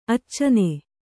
♪ accane